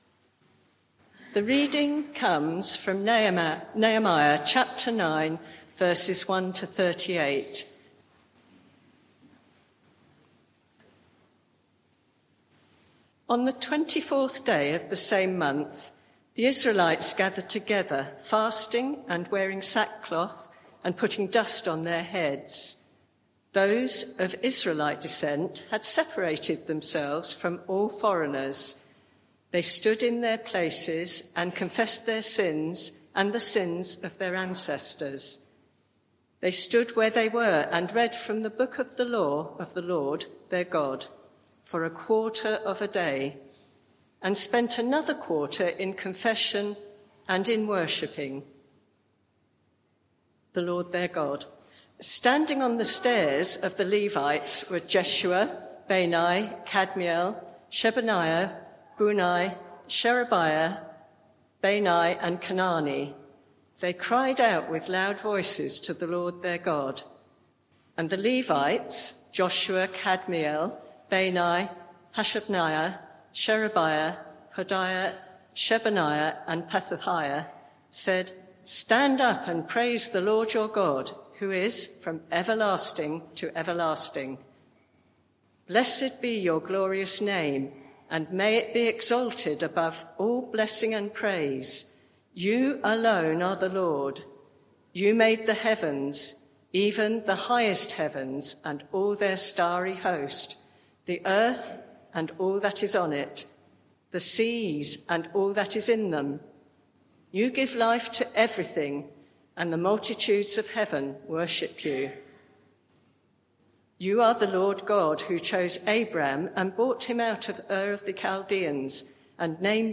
Media for 11am Service on Sun 02nd Jun 2024 11:00 Speaker
Sermon (audio)